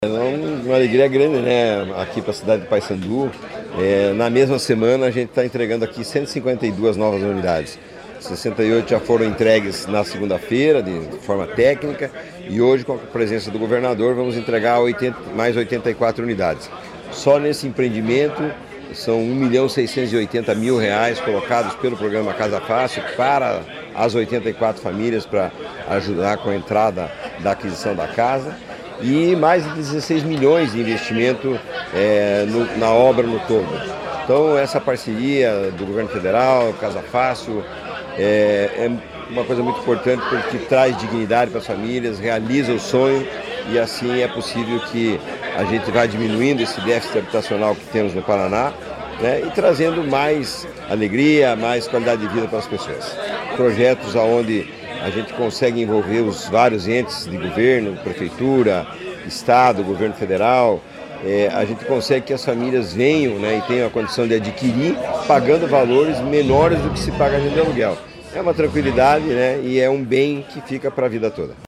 Sonora do presidente da Cohapar, Jorge Lange, sobre a entrega de residencial com 84 moradias em Paiçandu